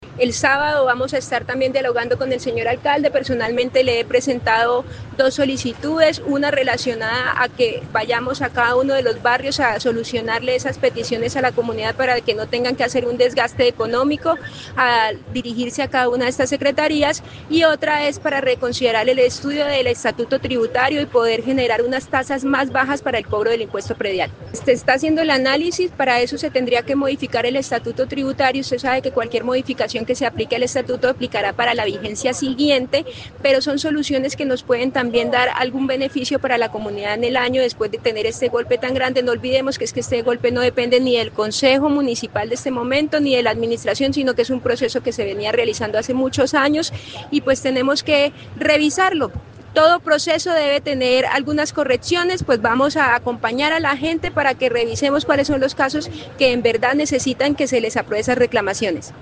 Marcela González Arias, concejal de filiación liberal, explicó que, este 15 de febrero se reunirán con el alcalde Monsalve Ascanio, para debatir esta y otras posibles apoyos y alivios a la hora de pagar el impuesto predial, como por ejemplo la posibilidad de exonerar algunos grupos poblacionales o sectores de la ciudad.
CONCEJAL_MARCELAA_GONZALES_PREDIAL_-_copia.mp3